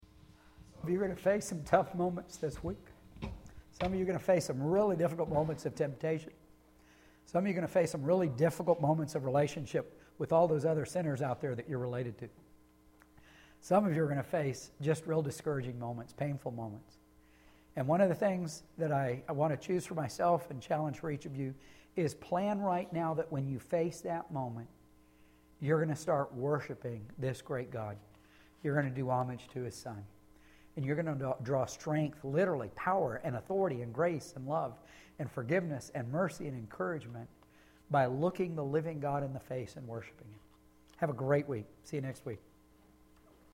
Benediction